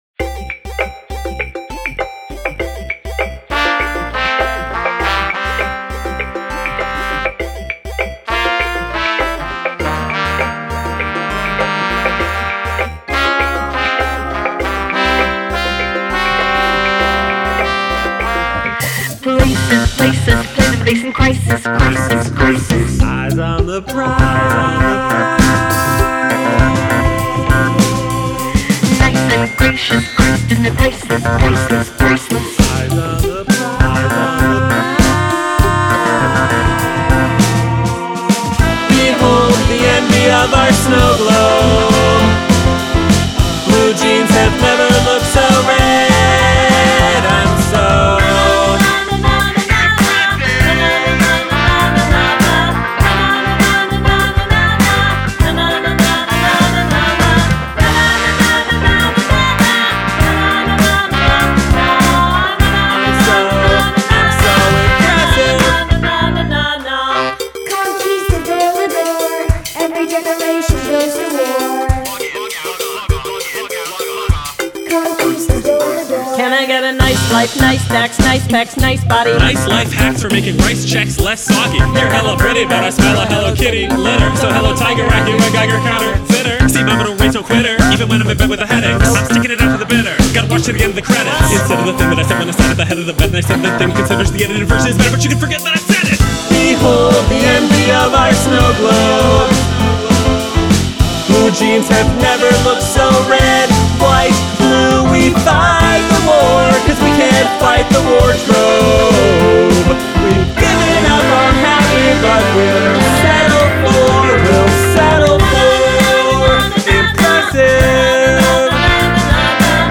additional vocals